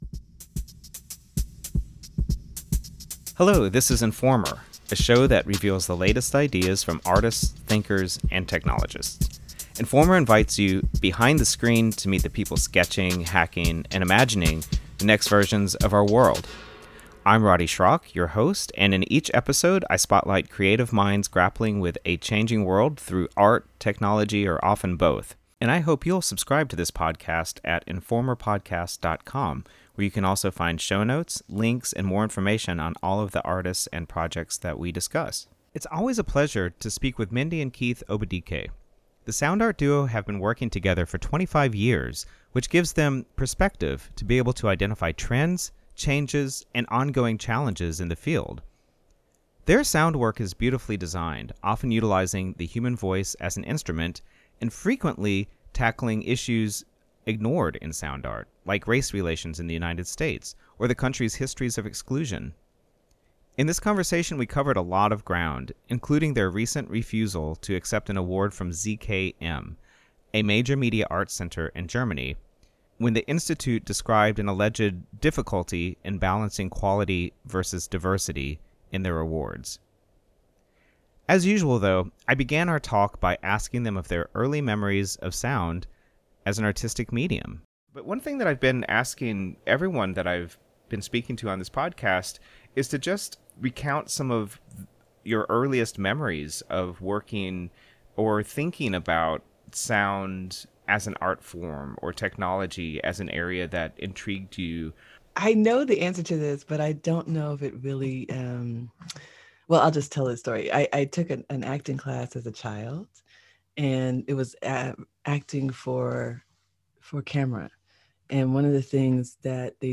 A conversation with sound artists